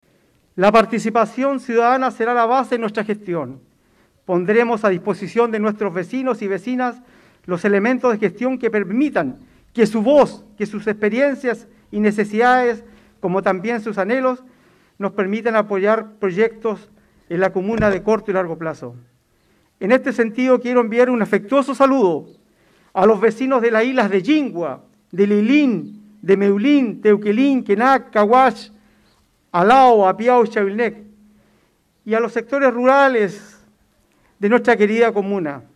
Quien también debuta como nuevo alcalde en el periodo 2021-2024, es René Garcés. El nuevo jefe comunal de Quinchao, señaló entre otras cosas que buscará tener una administración con gran participación ciudadana.